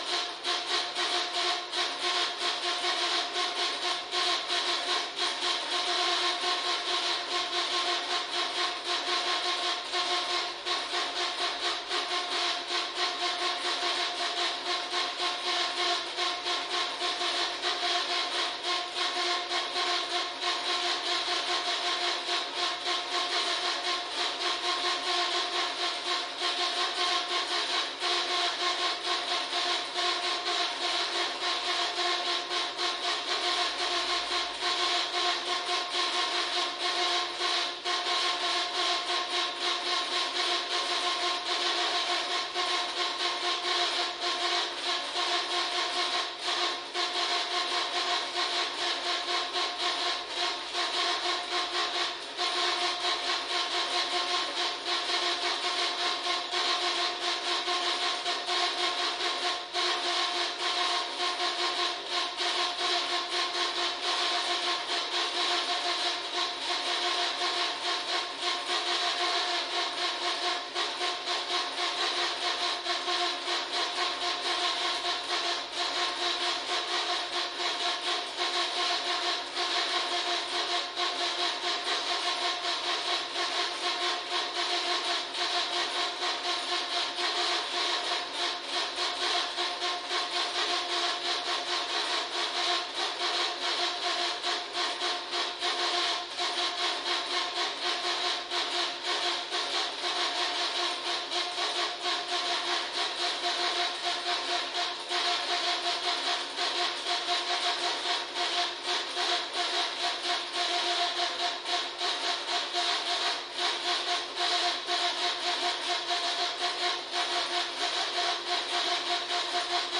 小狼嚎叫
描述：小狼嚎叫。 我使用了Yamaha Pocketra并使用Sony Sound Forge进行编辑。
标签： 小狼 哼唧 呼啸而过
声道立体声